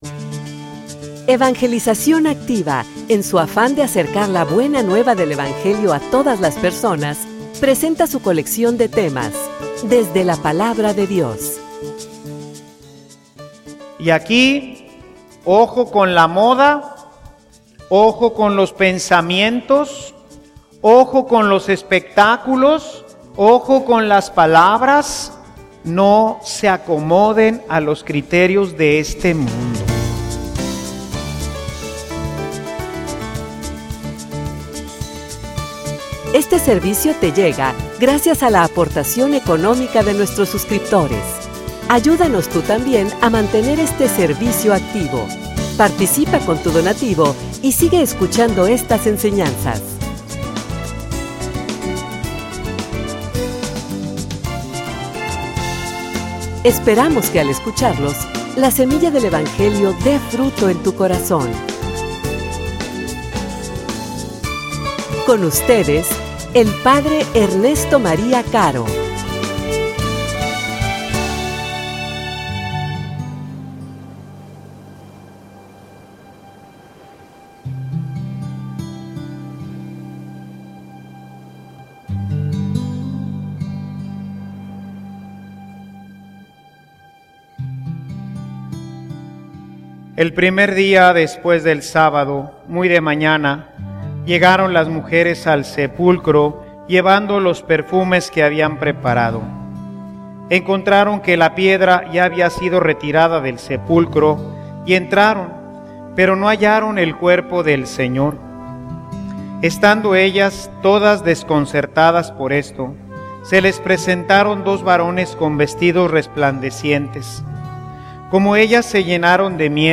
homilia_Aun_no_hemos_llegado.mp3